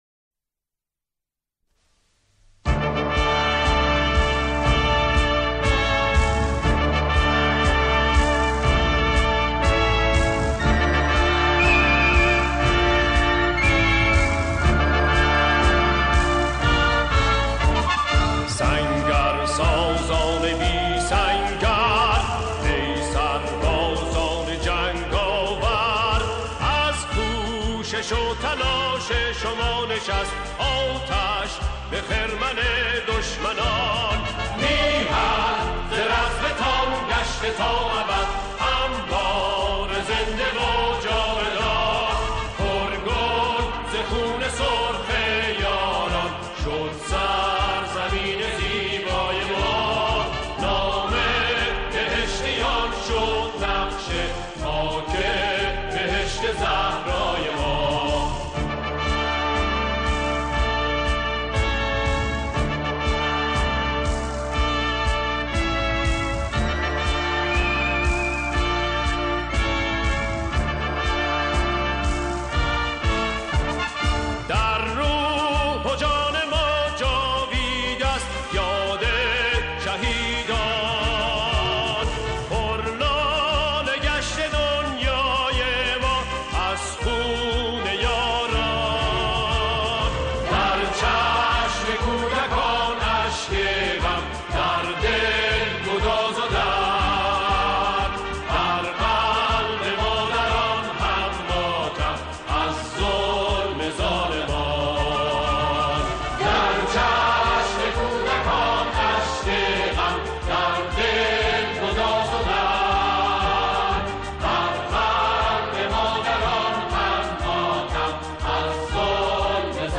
آکاپلا